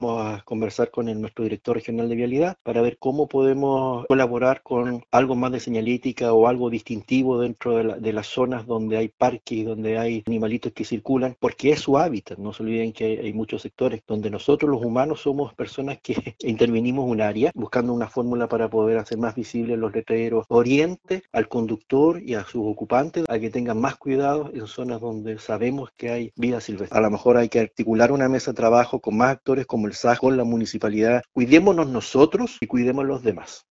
El Seremi de Obras Publicas, Juan Fernando Alvarado, indicó que se reunirán con el Director de Vialidad para buscar soluciones.
cuna-seremi-mop.mp3